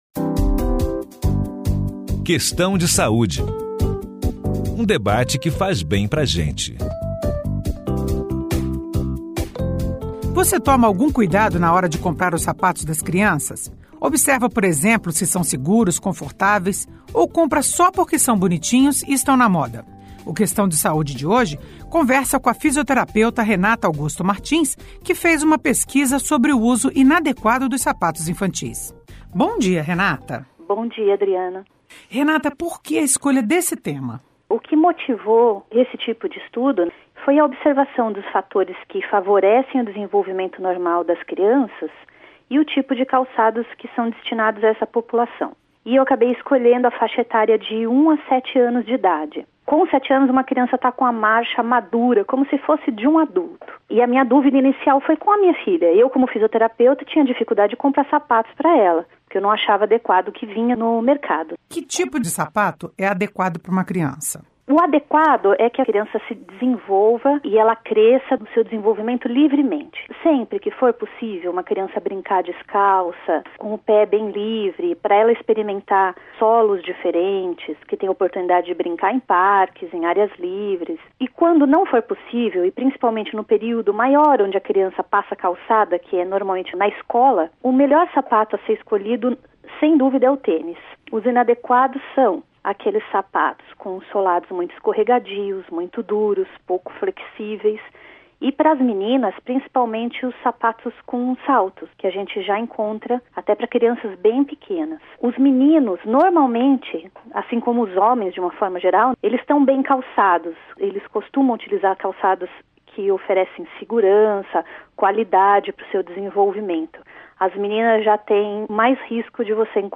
Entrevista com a fisioterapeuta